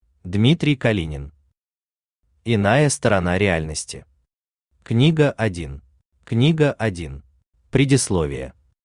Аудиокнига Иная сторона реальности. Книга 1.
Автор Дмитрий Витальевич Калинин Читает аудиокнигу Авточтец ЛитРес.